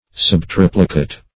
Search Result for " subtriplicate" : The Collaborative International Dictionary of English v.0.48: Subtriplicate \Sub*trip"li*cate\, a. (Math.)
subtriplicate.mp3